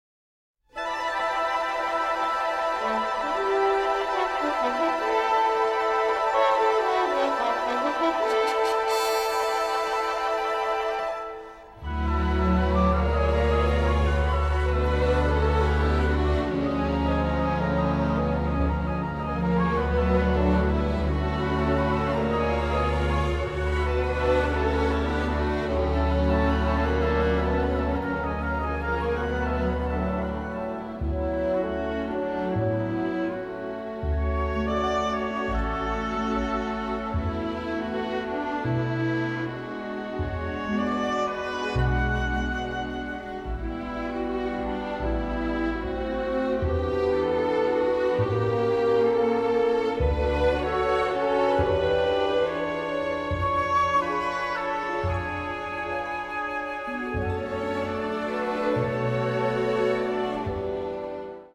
symphonic ensemble